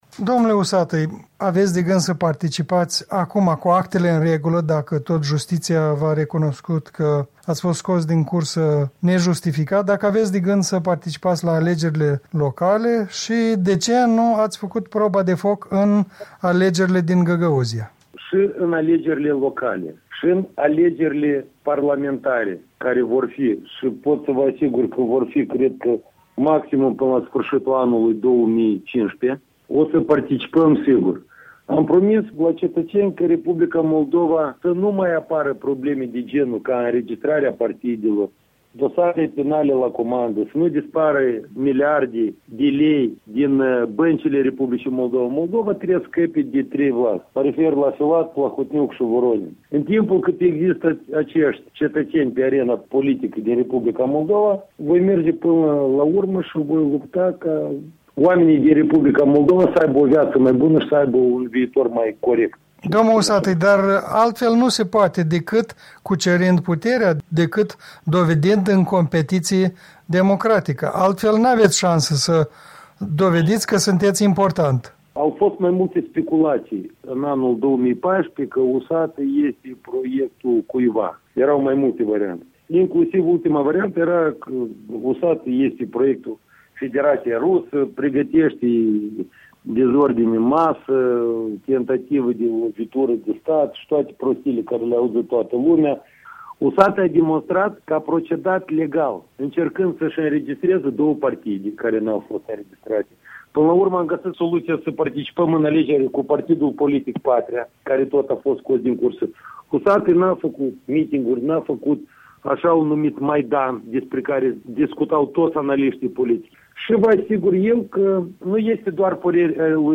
Un interviu cu liderul Partidului Patria